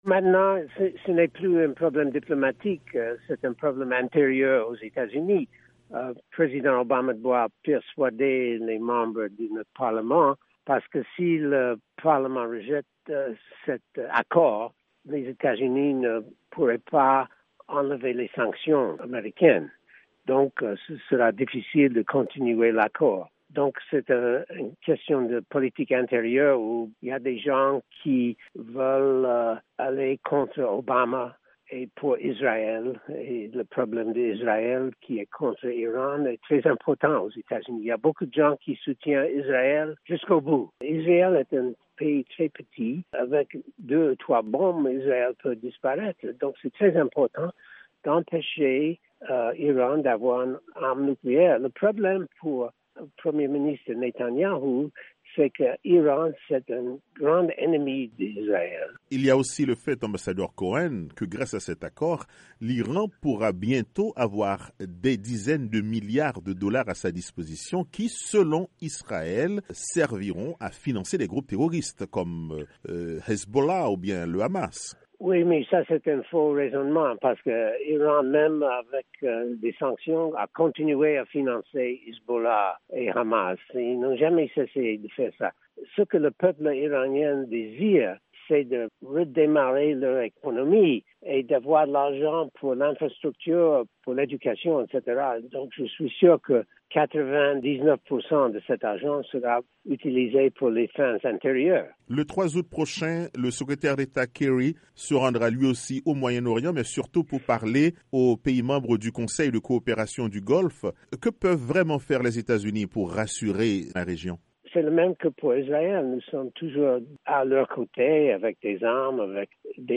L’ancien secrétaire d’Etat-adjoint aux Affaires africaines, Hermann Cohen, a fait sur VOA Afrique une analyse sur la démarche du président américain.